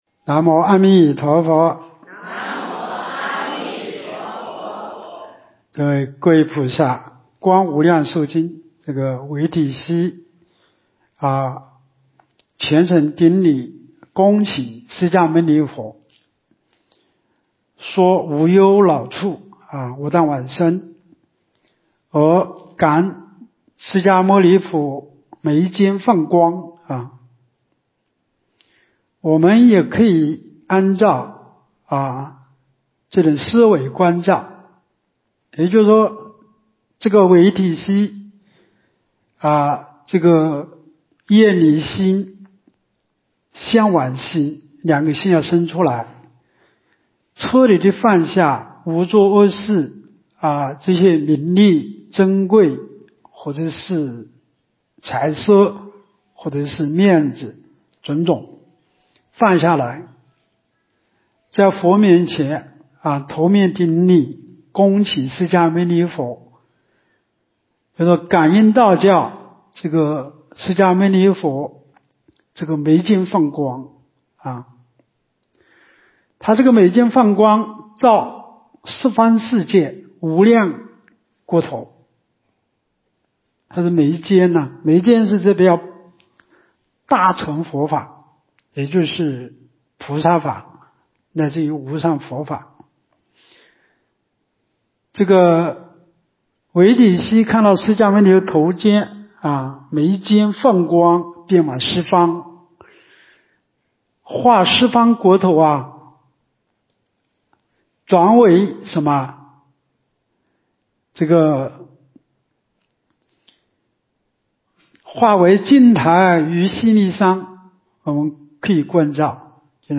【视频】2024冬季佛七开示（十一）（观经）
极乐法会精进佛七《观无量寿佛经》开示...